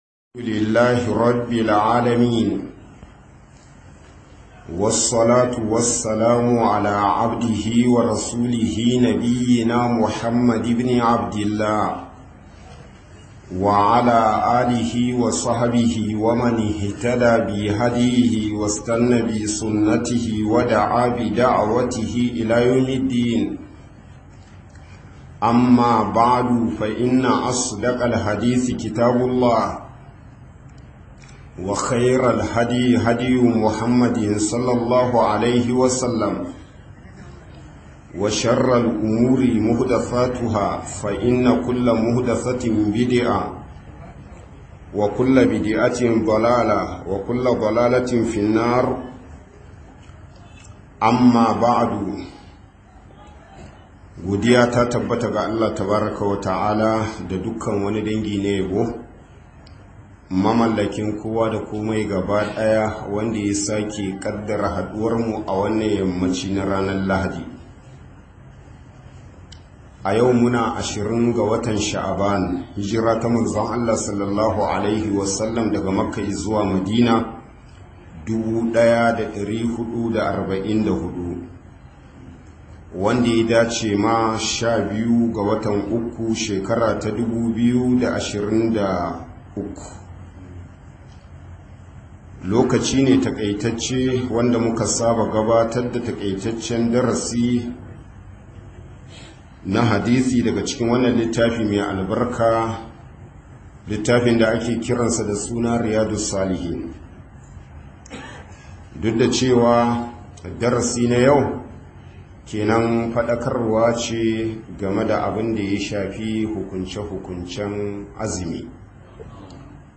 فضل الصيام - MUHADARA